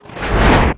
VEC3 Reverse FX
VEC3 FX Reverse 37.wav